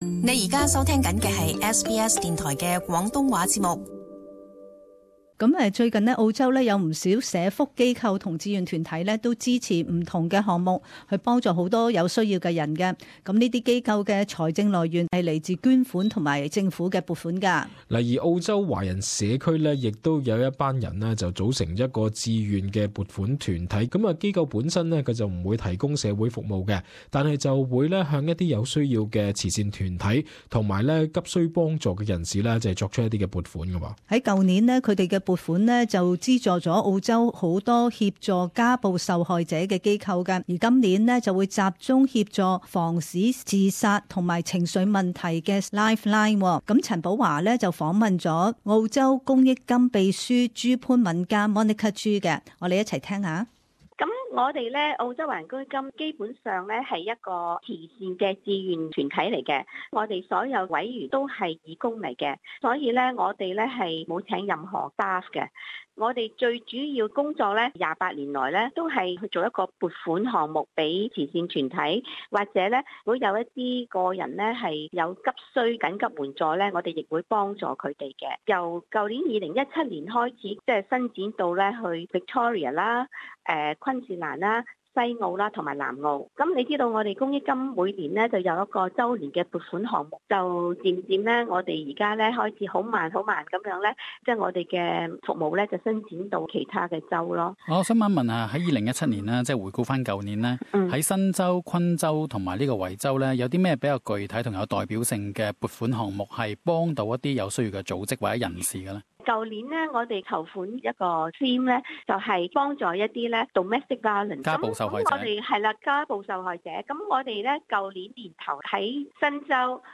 【社區專訪】澳華公益金撥款資助社區服務